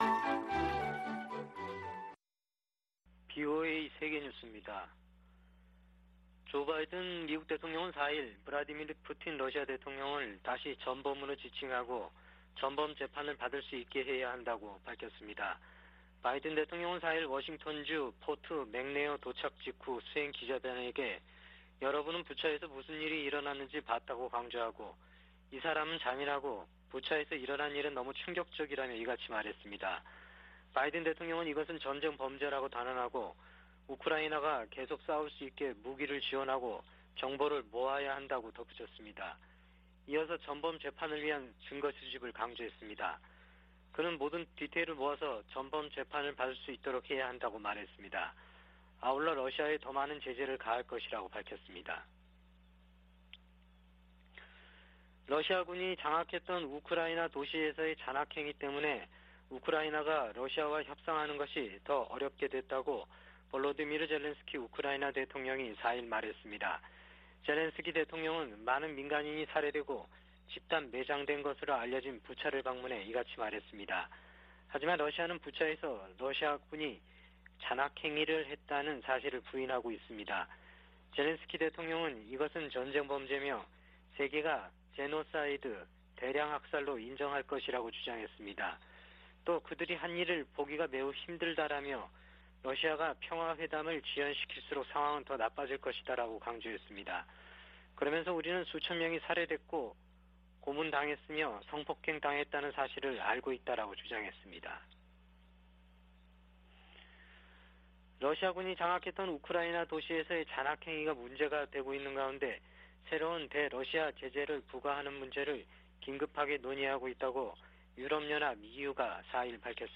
VOA 한국어 아침 뉴스 프로그램 '워싱턴 뉴스 광장' 2022년 4월 5일 방송입니다. 미국 정부가 대량살상무기와 탄도미사일 프로그램 개발에 관여한 정부 기관과 자회사 등에 추가 독자제재를 단행했습니다. 미 상원이 대북제재 관련 조항이 담긴 중국 견제 목적 법안의 수정안을 의결했습니다. 북한 김여정 노동당 부부장이 한국 국방부의 ‘사전 원점 타격’ 발언 후 대남 위협 담화를 발표했습니다.